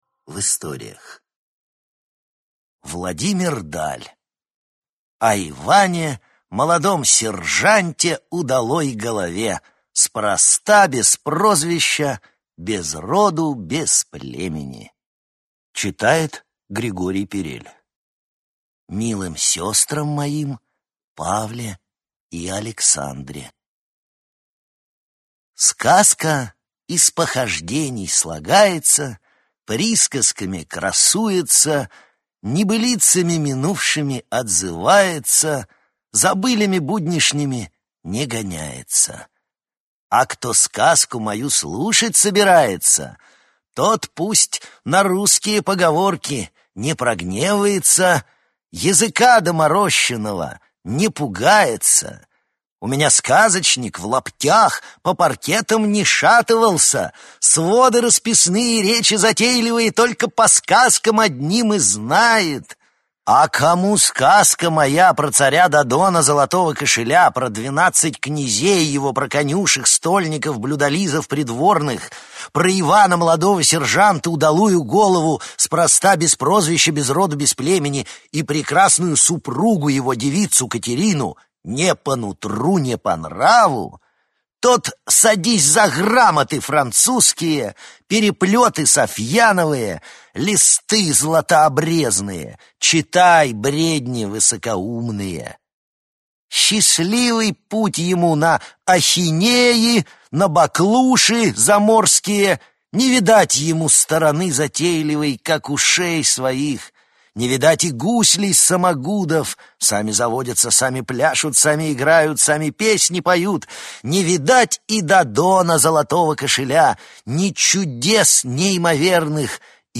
Аудиокнига Сказка об Иване Молодом сержанте, удалой голове | Библиотека аудиокниг